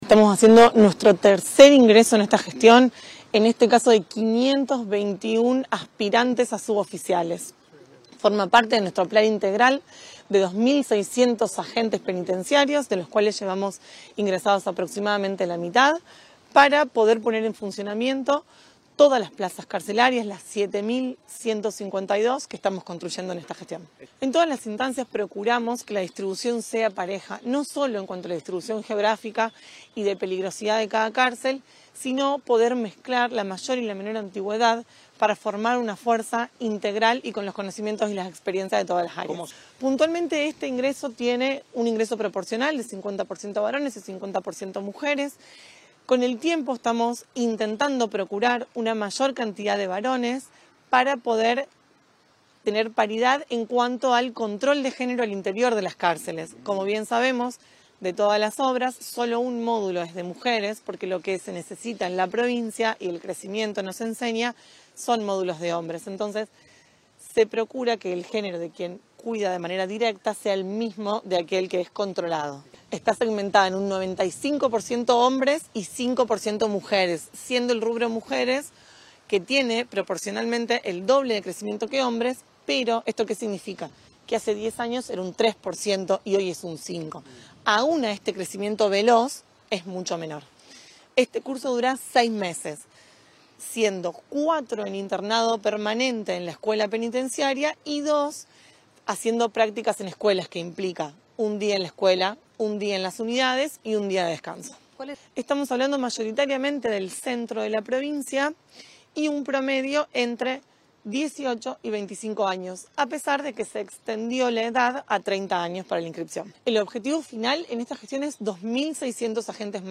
En una conferencia de prensa previa, Masneri precisó que se trata de la tercera convocatoria de aspirantes durante la actual gestión y que ya ingresó aproximadamente la mitad del total previsto.
Declaraciones de Masneri